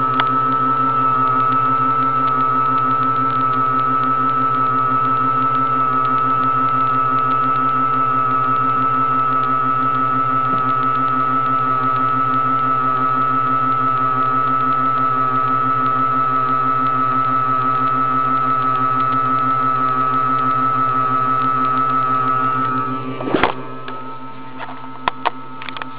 昨夜ちょっとうなりだした、My PC 　今朝から、大騒ぎしだしました～
こんな音だよ～ん(ーー;)→
urusai.WAV